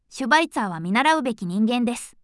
voicevox-voice-corpus / ita-corpus /No.7_ノーマル /EMOTION100_002.wav